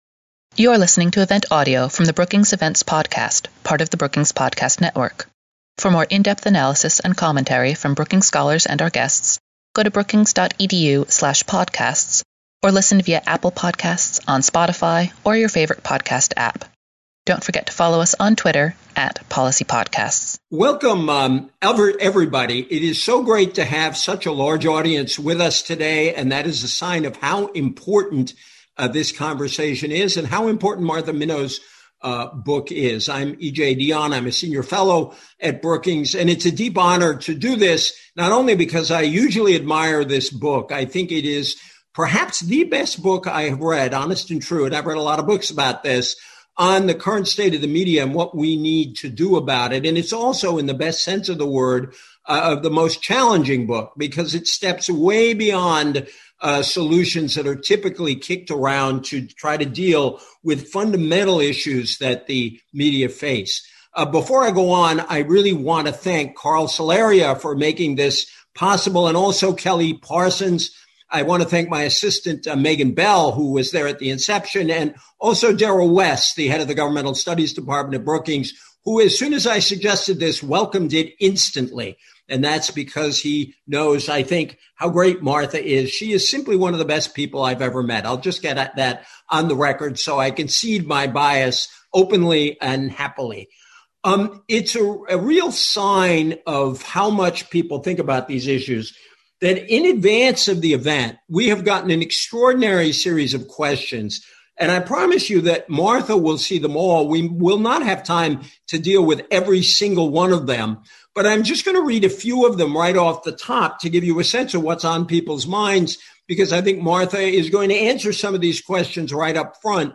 On July 29, Brookings will host Martha Minow for a conversation on her new book with Brookings Senior Fellow E.J. Dionne and Visiting Fellow Tom Wheeler. Speakers will explore the role of government in media, the nuances of the First Amendment, and potential reforms.